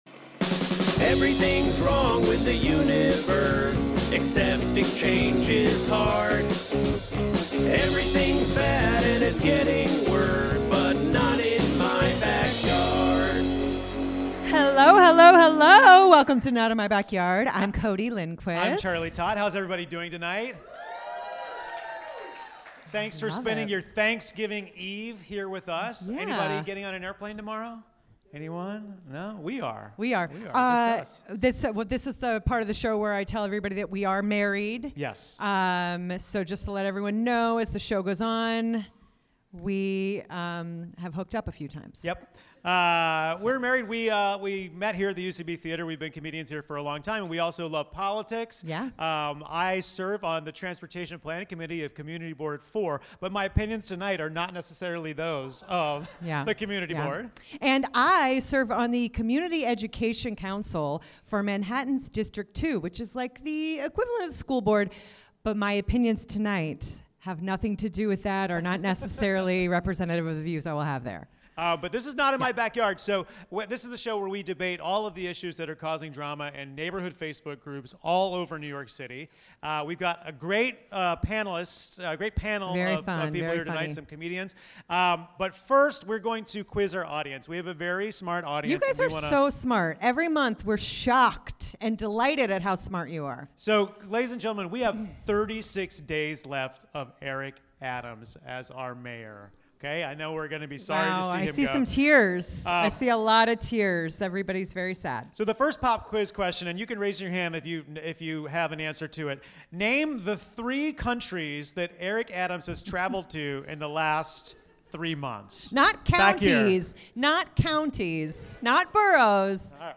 Live form the UCB Theatre NY, we discuss Mamdani's meeting with Trump, SUV puppy mills, and Fan Man.